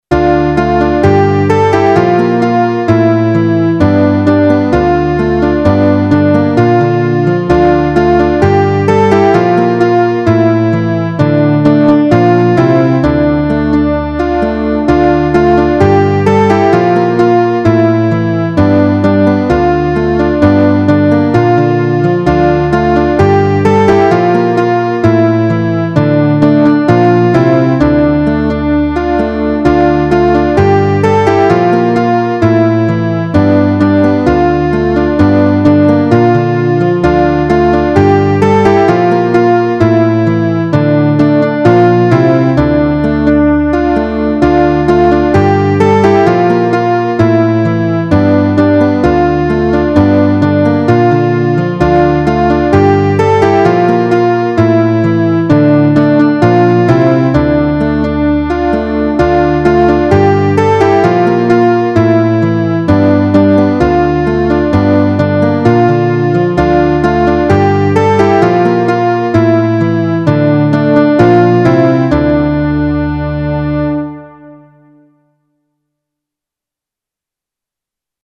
A rousing Psalm of praise to God our Provider.